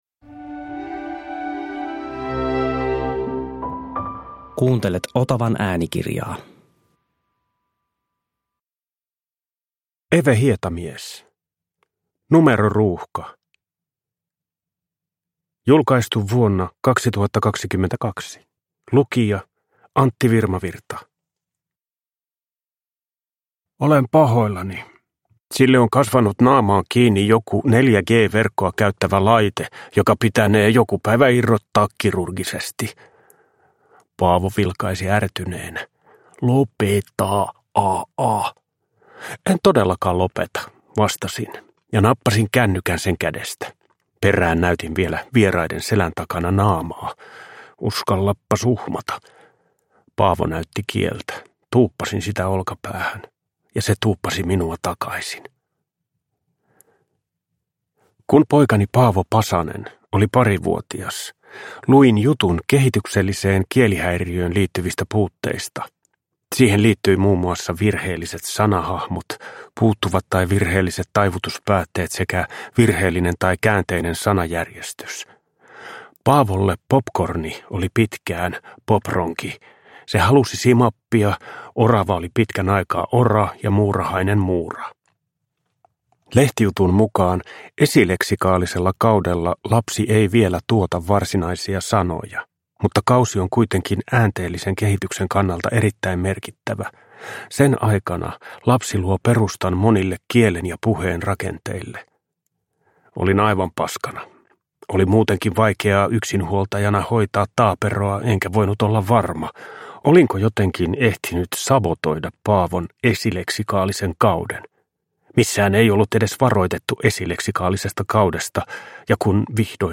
Numeroruuhka – Ljudbok